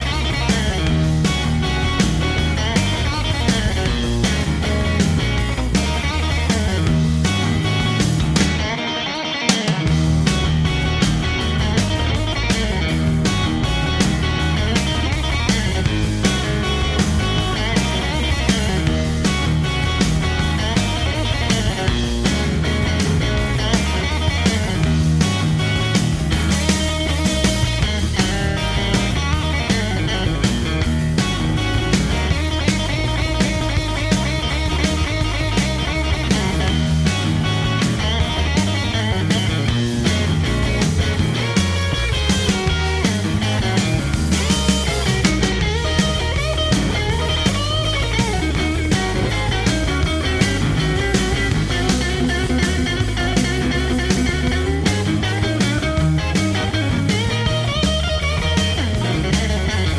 Рок
виртуоз блюзовой гитары